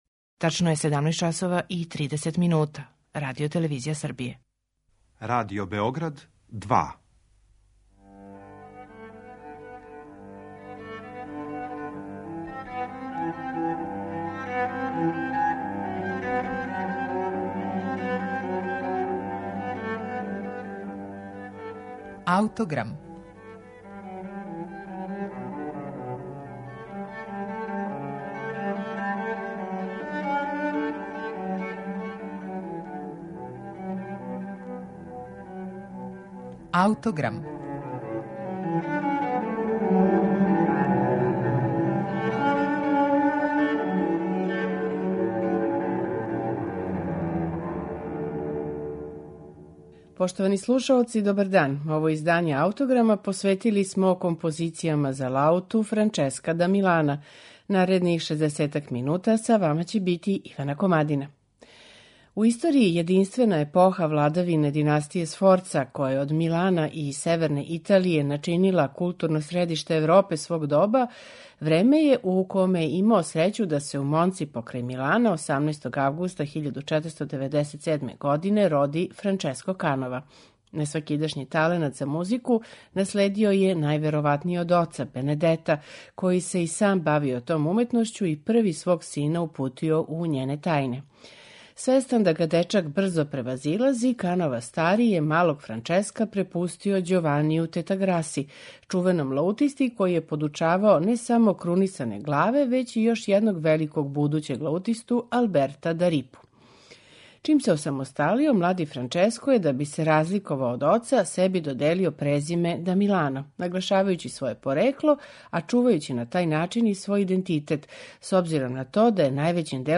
У данашњем Аутограму слушаћемо 21 комад за лауту Франческа да Милана у слободно компонованом редоследу ричеркара, фантазија, шансона и мадригала, који је остварио лаутиста Пол О'Дет, један од највећих стручњака за тумачење његовог опуса.